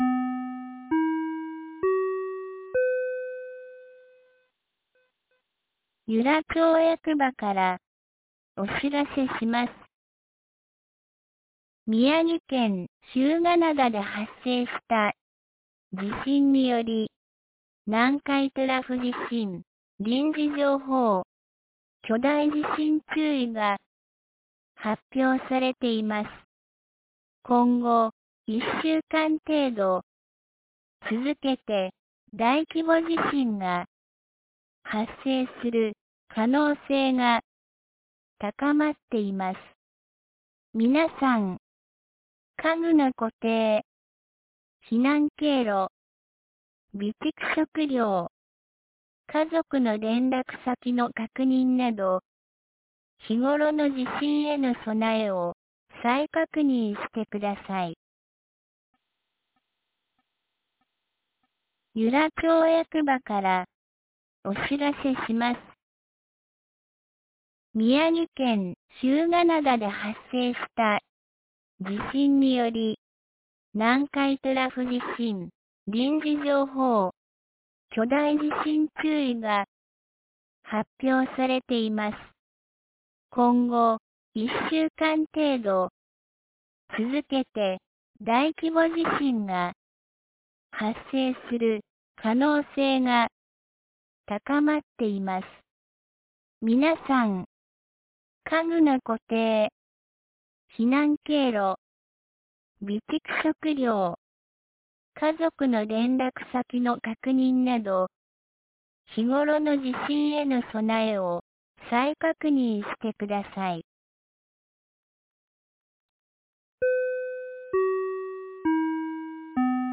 2024年08月08日 20時34分に、由良町から全地区へ放送がありました。